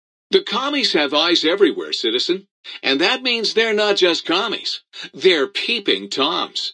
Category: Old World Blues audio dialogues Du kannst diese Datei nicht überschreiben.